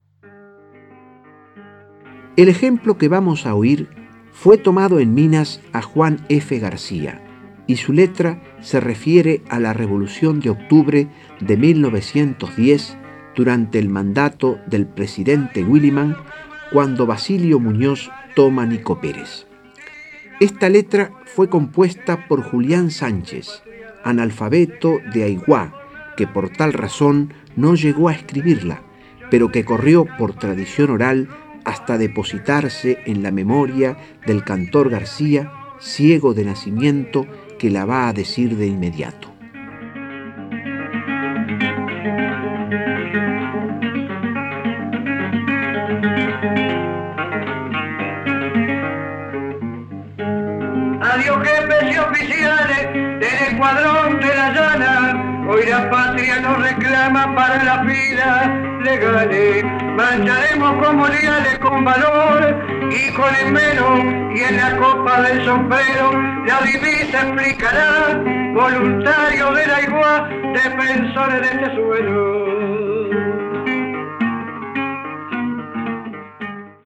Locución: Lauro Ayestarán
Grabación de campo emitida en la audición y utilizada en este micro radial:
Especie: milonga
Localidad: Montevideo (en los estudios del Sodre), departamento de Montevideo, Uruguay